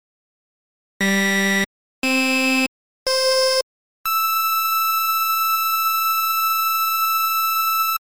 Diva Aliasing?
Ein kleiner Test zur überragenden Klangqualität von Diva.
Und das war im "Great Modus", nicht "Draft".
Warum klingt das so unrein?
Vielleicht doch Aliasing, was meinst?